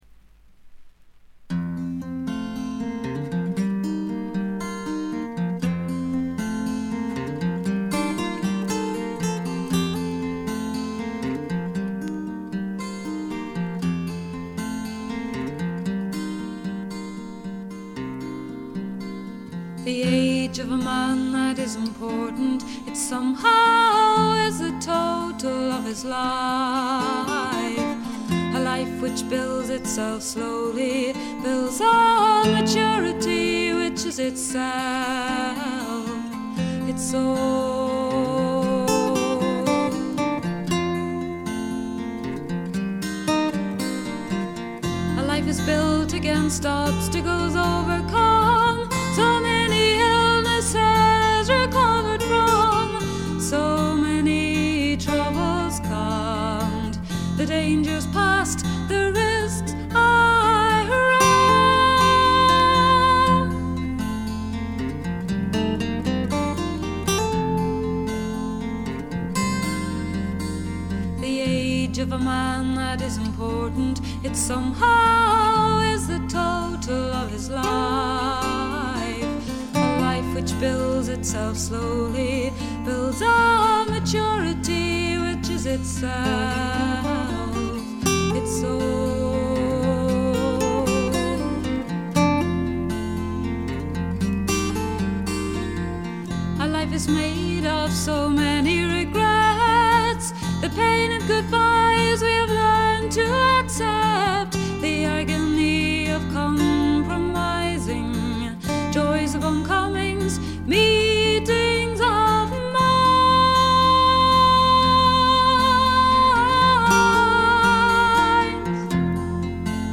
アイルランドの女性シンガー・ソングライターでトラッドと自作が半々という構成。
天性のとても美しい声の持ち主であるとともに、歌唱力がまた素晴らしいので神々しいまでの世界を構築しています。
試聴曲は現品からの取り込み音源です。
Bass
Cello
Lead Guitar [Acoustic]